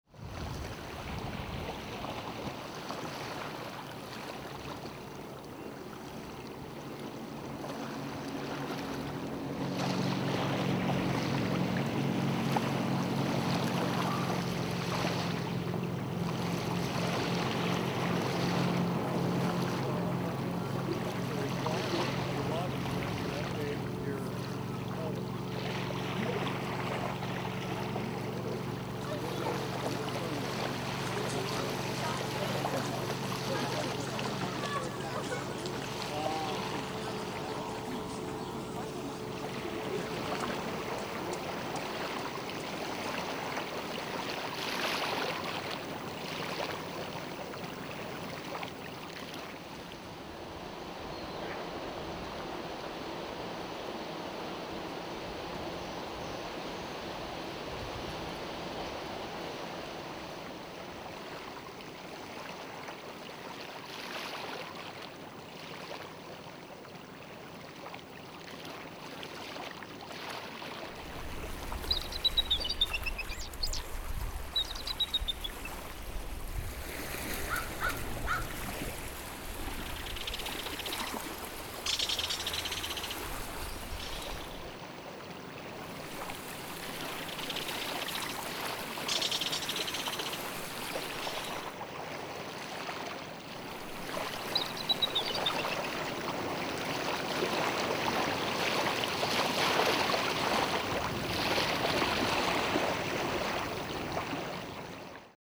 Boats are moving up and down Hood Canal, and their wakes crash against the stones on the beach. Speedboats, sailboats, fishing vessels, small yachts, kayaks…the voices of the people inside of them carry across the water, bounce off the feeder bluffs behind me, and trickle down to my ears. It sounds like a low phone conversation with bad reception.
The waves, varying in size because of the activity out on the water, crash, pull, and repeat on the rocky beach.
At the base of the bluff, where I have set up my towel, a red alder whispers in the wind. Birds sing and call — notably Pacific slope flycatcher, bald eagle, and the occasional crow. Across the water, faster than the boats this close to shore, a belted kingfisher chatters and swoops.